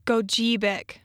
(gō-GĒ-bik)